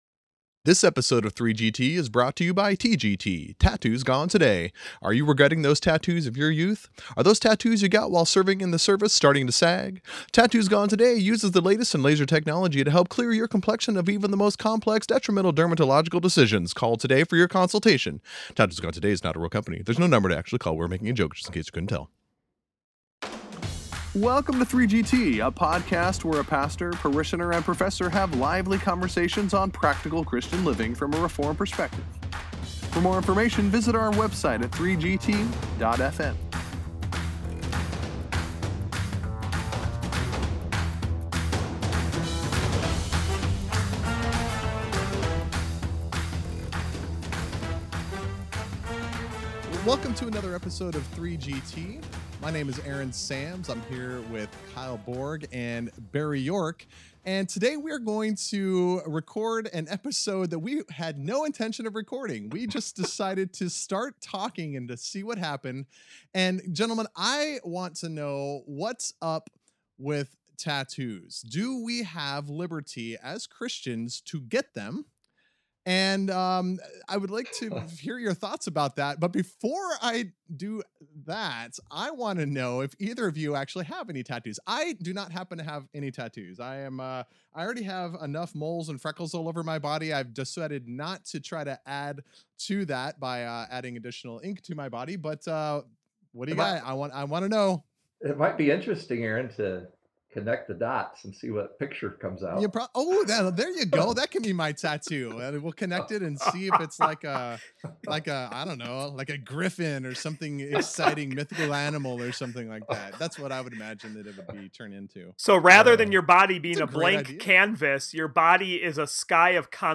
Between recording planned episodes, a conversation started between the Three Guys Theologizing, of all things, tattoos.
So join this invigorating and humorous discussion that explores such things as Leviticus 19:28, the adiaphora, and cultural influences.